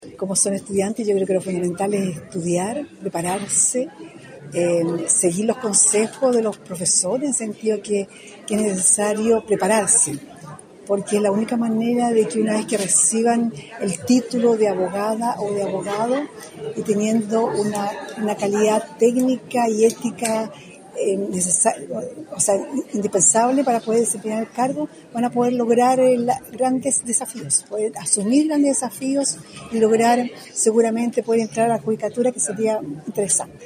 Presidenta de la Corte Suprema encabezó acto de conmemoración del 8M en la UdeC - Radio UdeC